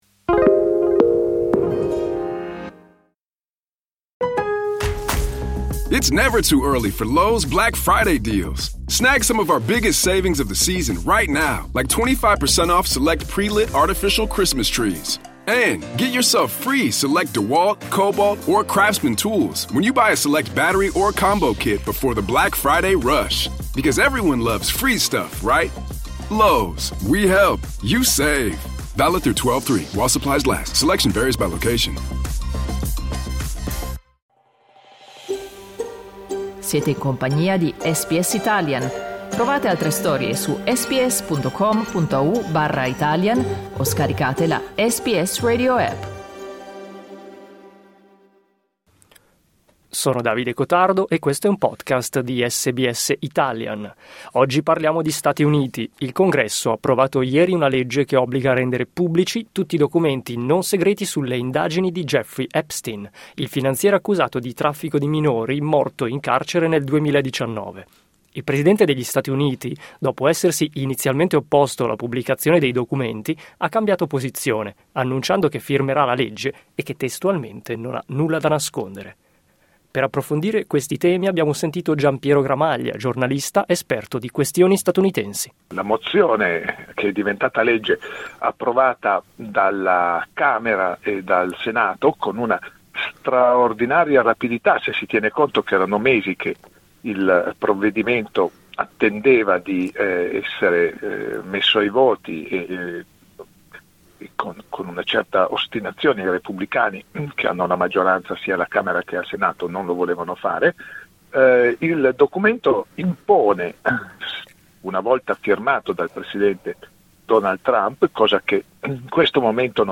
Clicca sul tasto "play" in alto per ascoltare l'intervento del giornalista